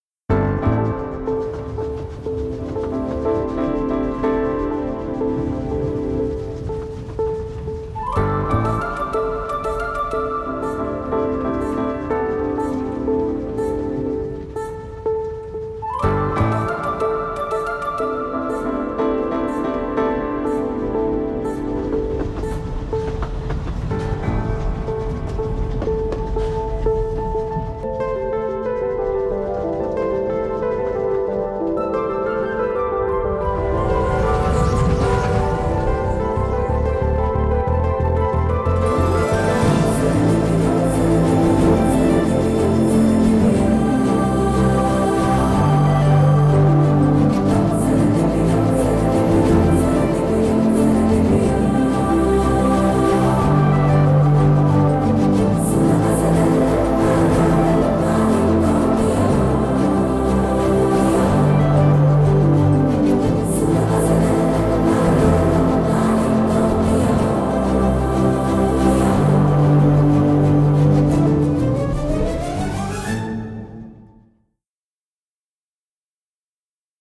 without disturbing sounds and dialogues.